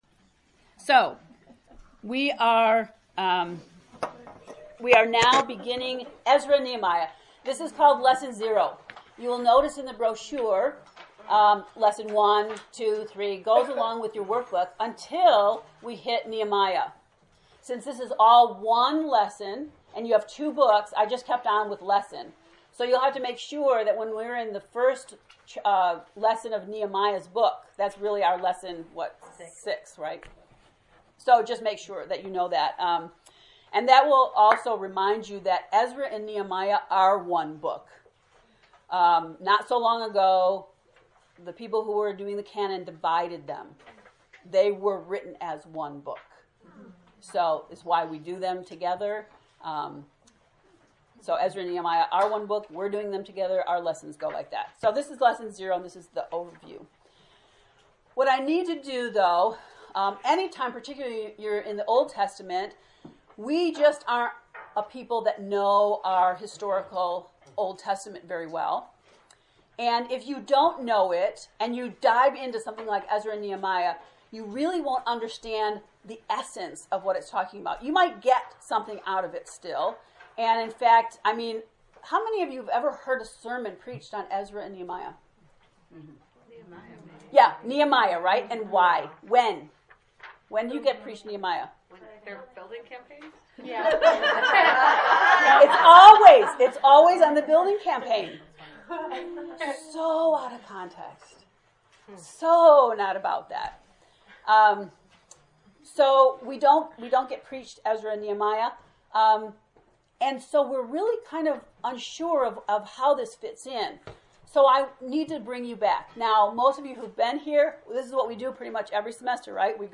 To listen to EZRA lecture 0, Overview, click below: